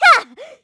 Hilda-Vox_Attack1_kr.wav